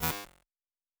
pgs/Assets/Audio/Sci-Fi Sounds/Electric/Glitch 1_10.wav
Glitch 1_10.wav